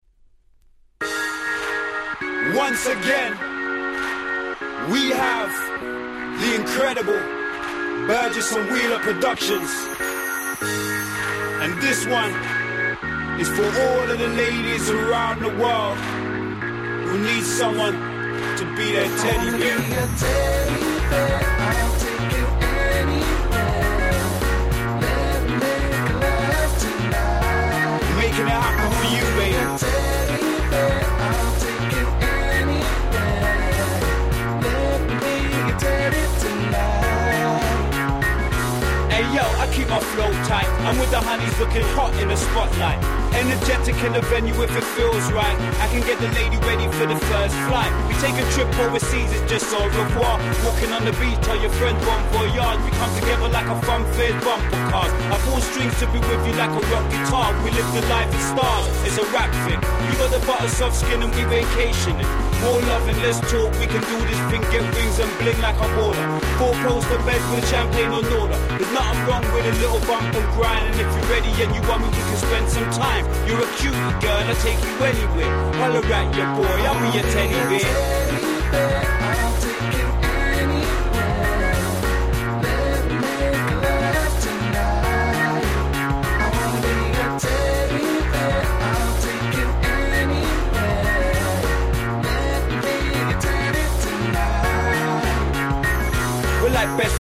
07' Nice Cover R&B!!